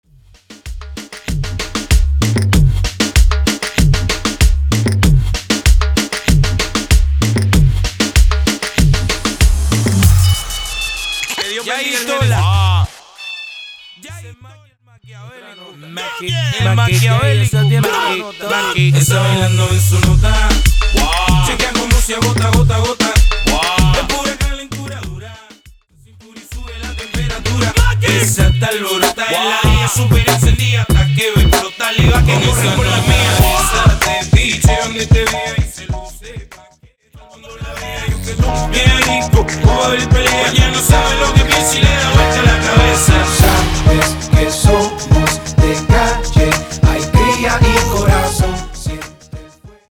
Mashup Dirty